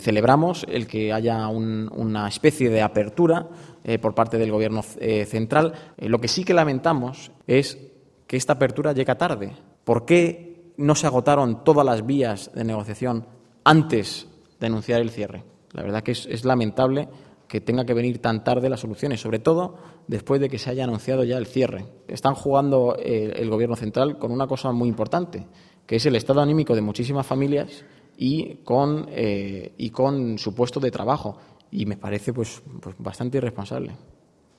Vicepresidencia Primera Miércoles, 2 Septiembre 2015 - 1:00pm Sobre la propuesta del Gobierno de Mariano Rajoy de aportar 27 millones de euros al año para asegurar la viabilidad de la central termoeléctrica de Puertollano y tras recordar la reunión que el presidente Emiliano García-Page mantendrá con la dirección de la empresa, el portavoz se ha congratulado por esta “especie de apertura” pero ha lamentado que llegue tarde, cuando el debate sobre el futuro de la planta dura ya más de dos años. consejogobierno_elcogas.mp3